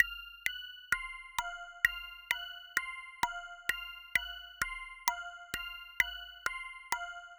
MB - Loop 5 - 65BPM.wav